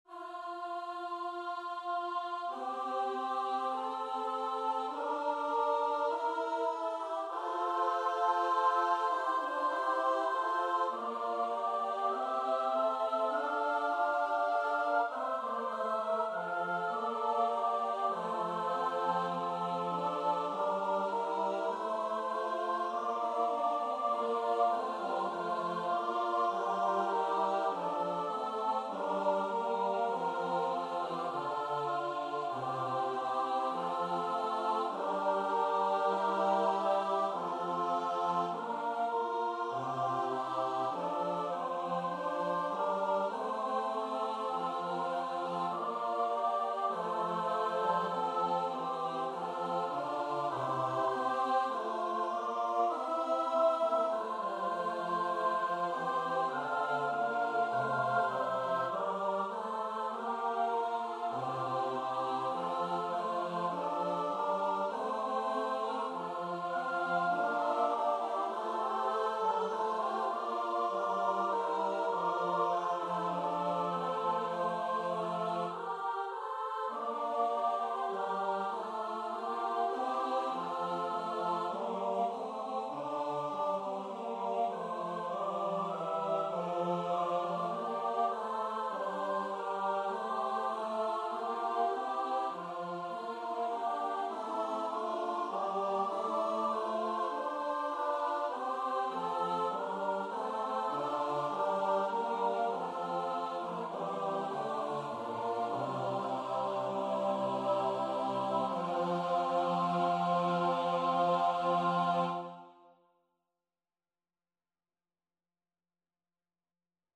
4/4 (View more 4/4 Music)
Choir  (View more Intermediate Choir Music)
Classical (View more Classical Choir Music)